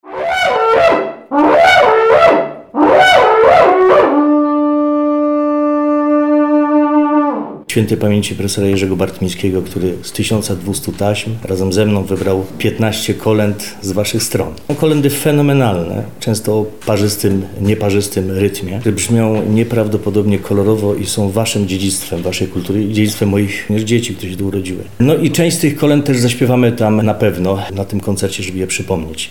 muzyk i kompozytor